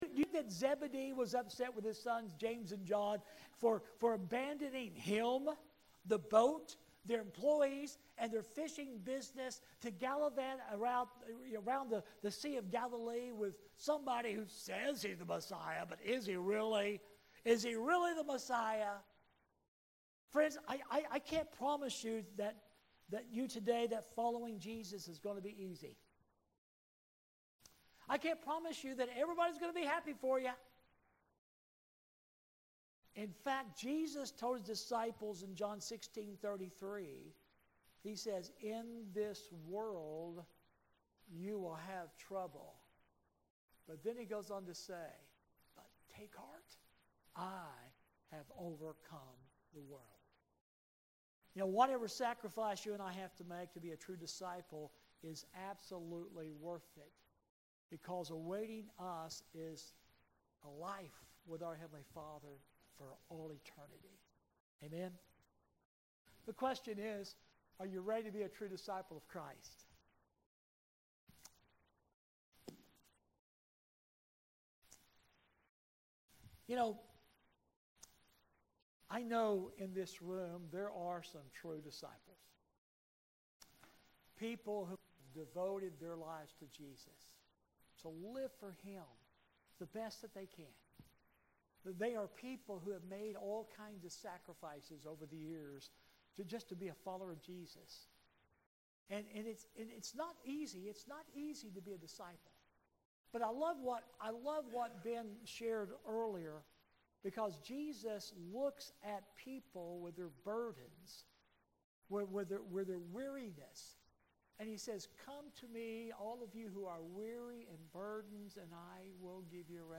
SUNDAY LESSON, 10/5/25